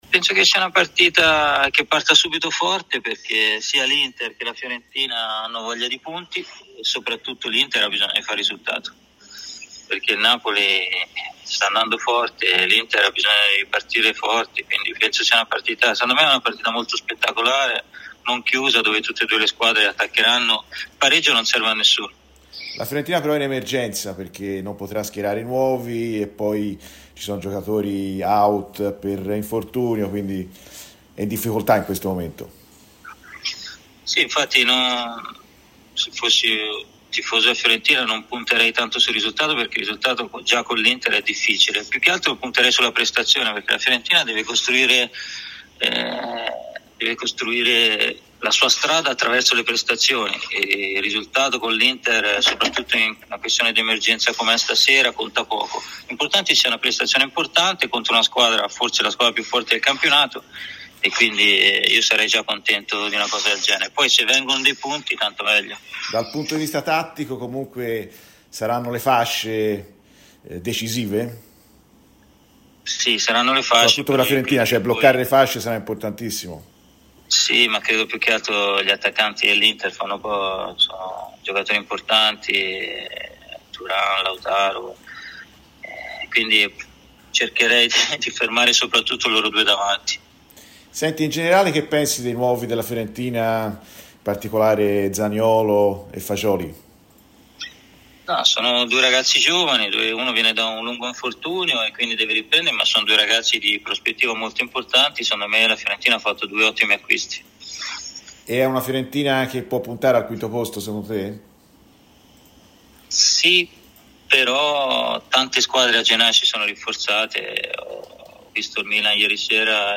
Cristiano Zanetti, doppio ex della sfida tra Fiorentina e Inter, è intervenuto nel corso di Viola Amore Mio su Radio FirenzeViola presentando così la sfida di questa sera: "Penso che sia una partita che partirà subito forte perché entrambe le squadre hanno bisogno di punti e perché l'Inter ha bisogno di vincere perché il Napoli sta andando forte. Sarà una gara spettacolare, non chiusa perché il pareggio non serve a nessuno".